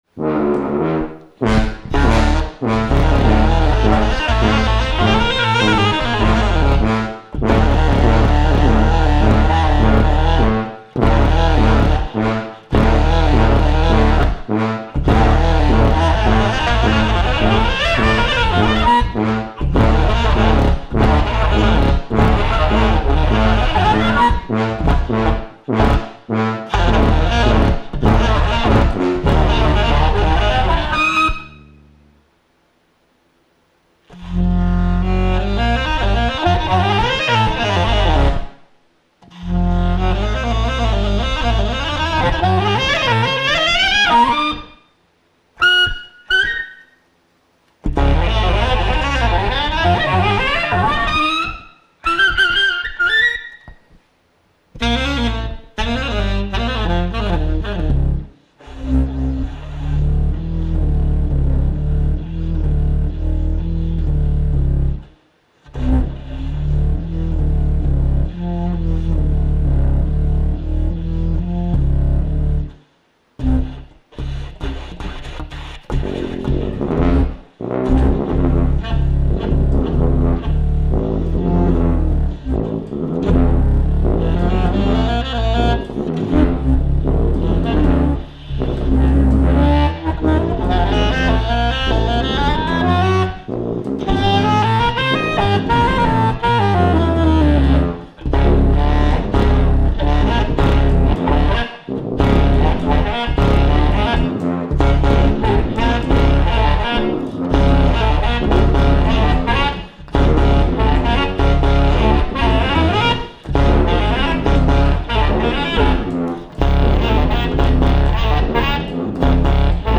Recorded live at ABC NoRio in Manhattan
Stereo (Pro Tools)